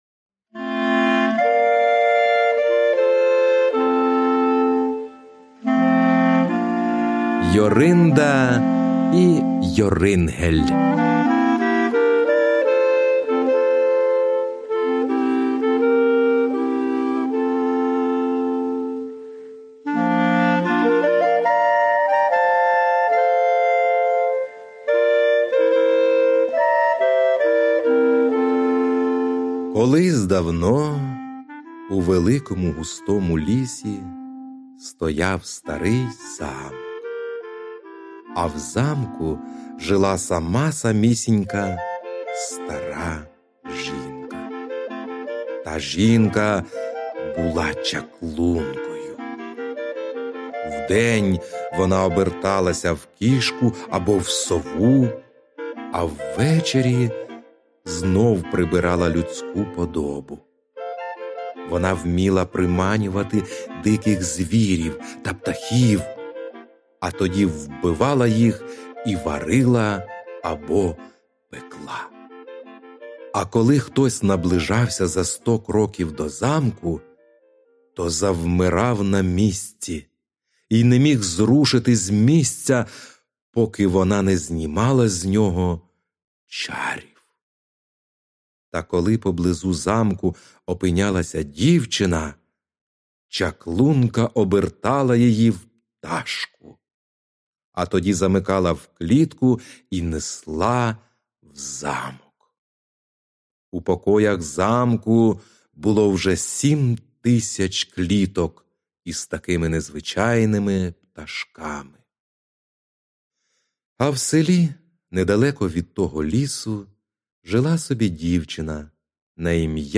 Аудіоказка Йоринда і Йорингел